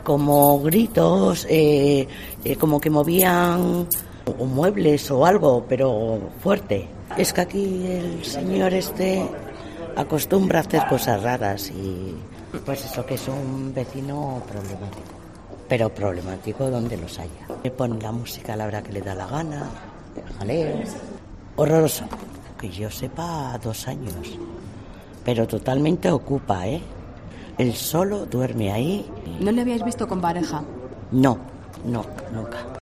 Una vecina del local donde fue secuestrada la mujer describe al agresor